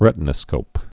(rĕtn-ə-skōp)